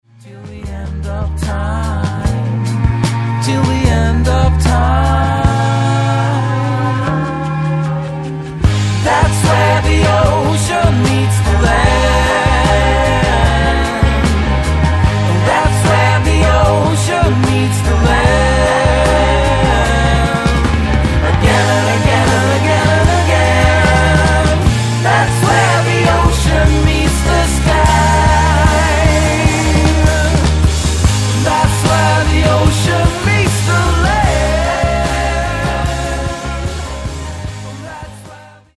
Guitar Pop/Swedish